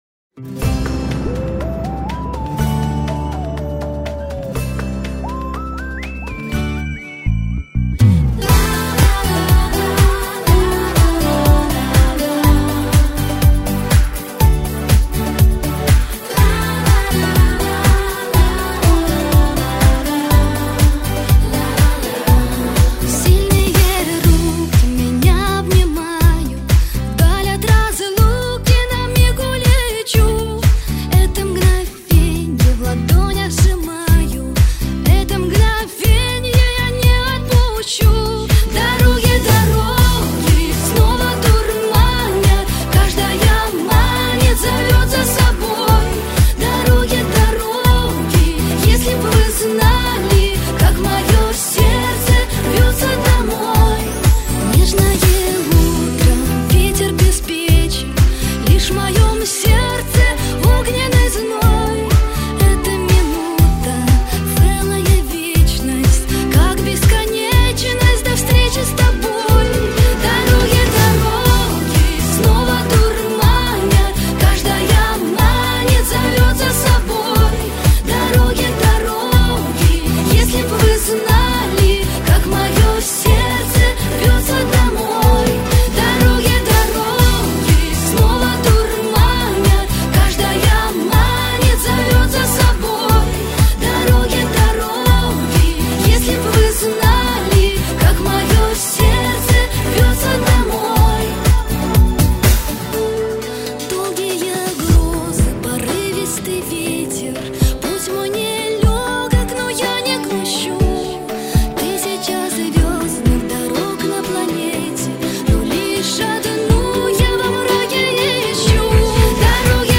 ШАНСОН в МАШИНУ